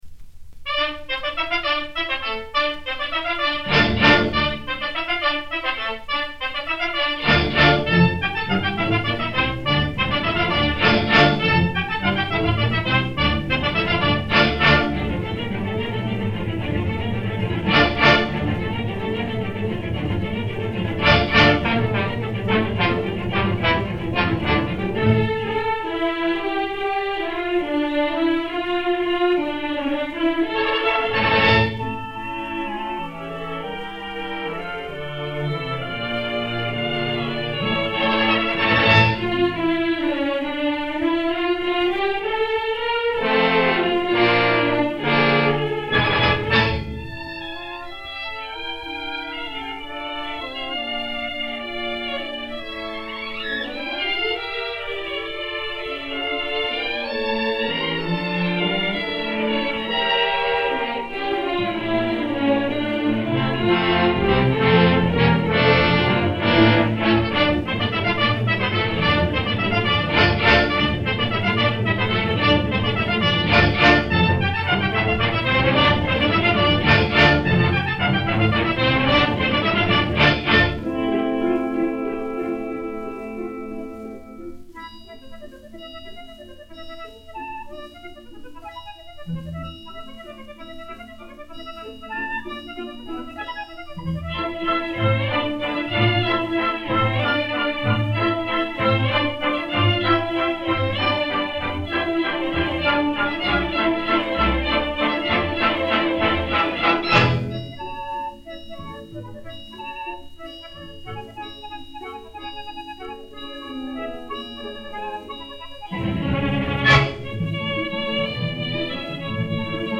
Orchestre Symphonique dir Pierre Chagnon